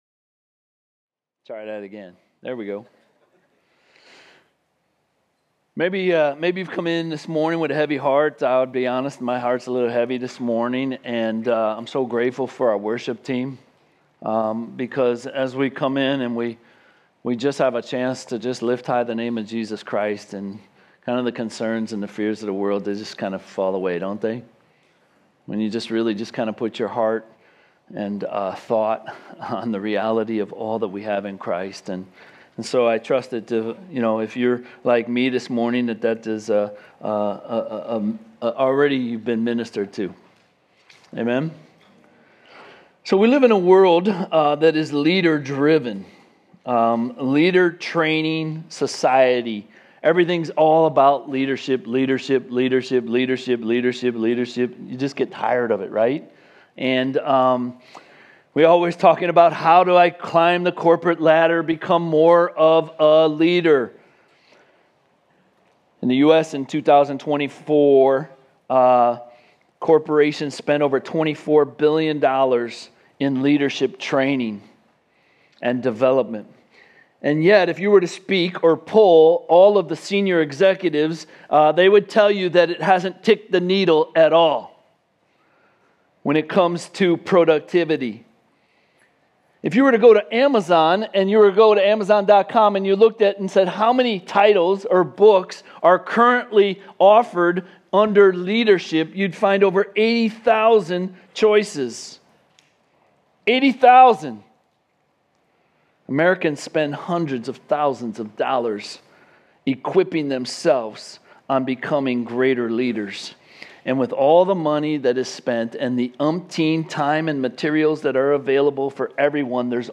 Home Sermons Cornerstone